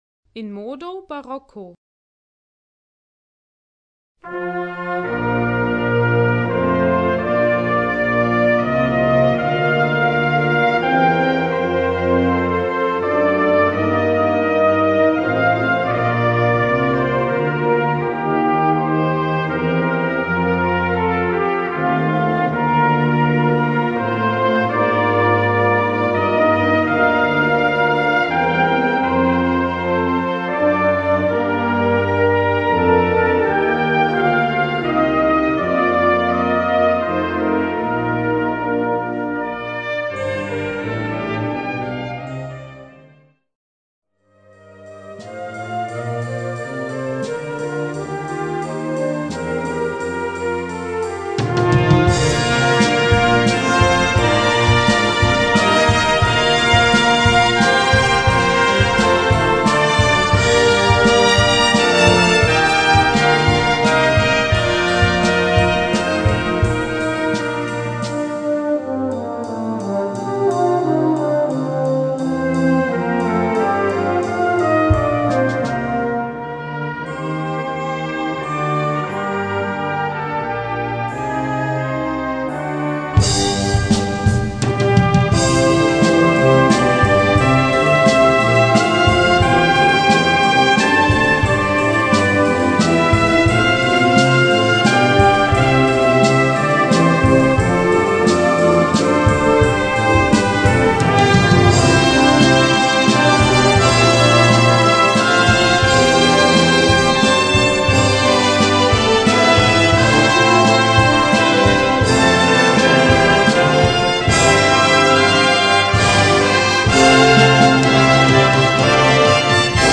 Gattung: Konzertstück
Besetzung: Blasorchester
Genießen Sie die Barockelemente in modernem Kleid.